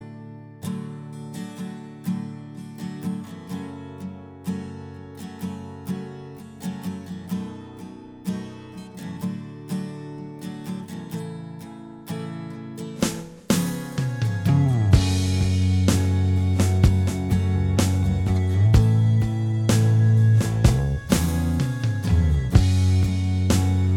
Minus Electric Soft Rock 3:23 Buy £1.50